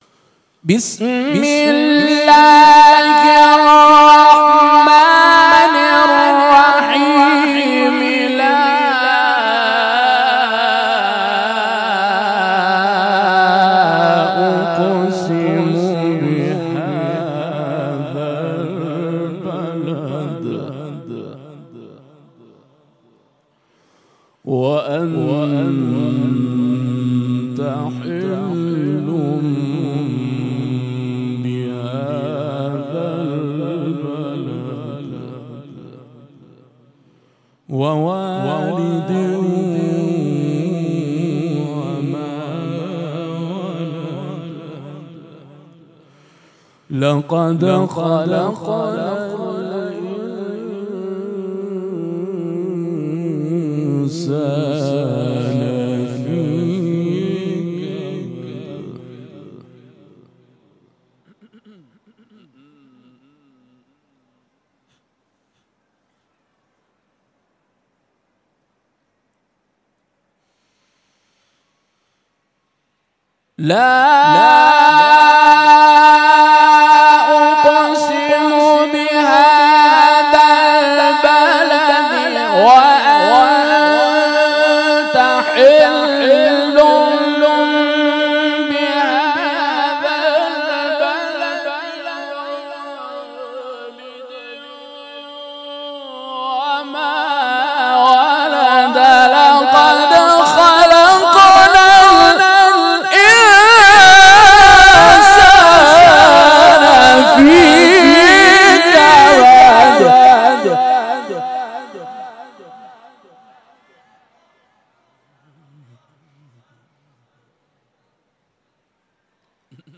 تلاوت
از سوره «بلد» و «حمد» در محفل انس با قرآن و عترت صادقیه شهرستان اردکان